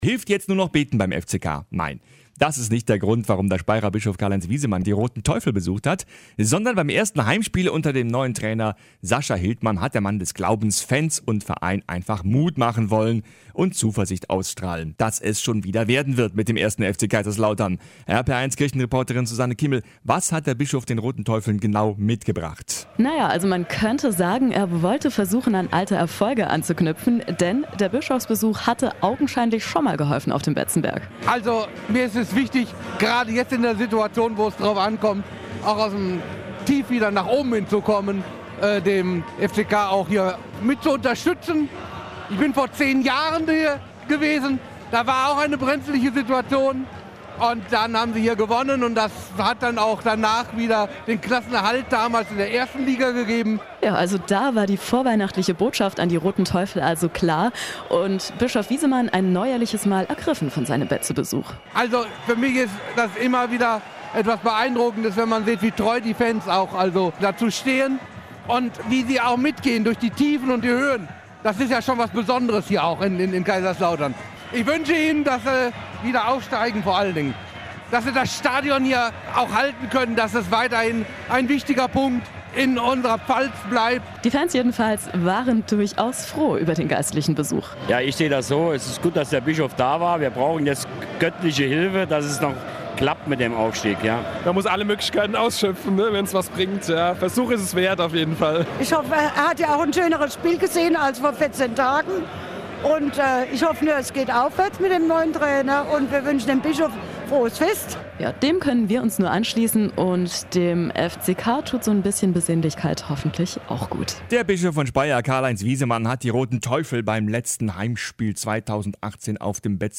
RPR1. Beitrag zum Besuch von Bischof Wiesemann auf dem Betzenberg